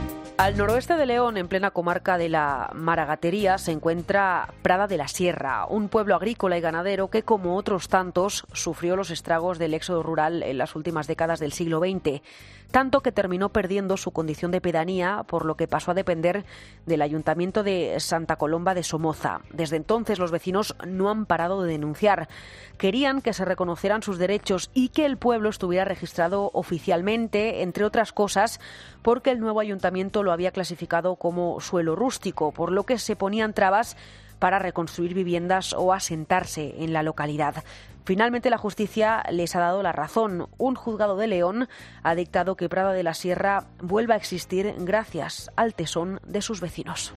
Presentado por Carlos Herrera, el comunicador más escuchado de la radio española, es un programa matinal que se emite en COPE, de lunes a viernes, de 6 a 13 horas, y que siguen cada día más de dos millones y medio de oyentes, según el EGM.